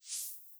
Modern UI SFX / SlidesAndTransitions